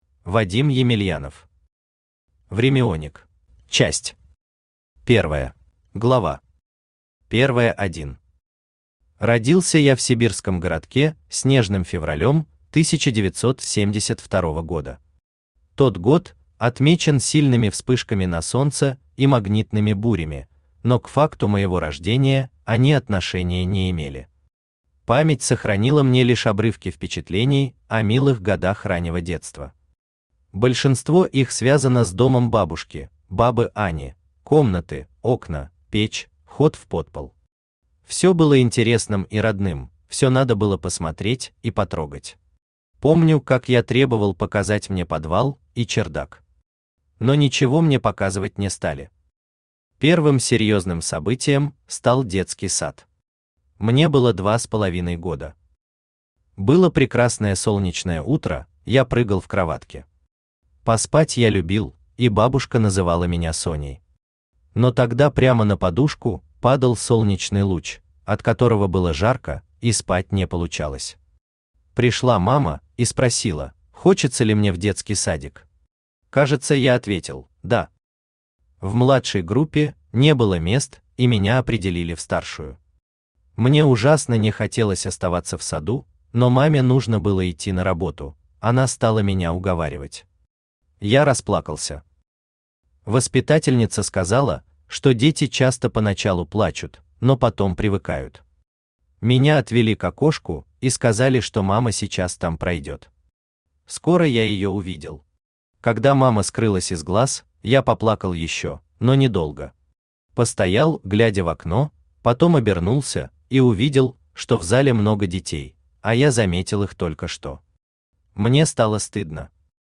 Аудиокнига Времяоник | Библиотека аудиокниг
Aудиокнига Времяоник Автор Вадим Юрьевич Емельянов Читает аудиокнигу Авточтец ЛитРес.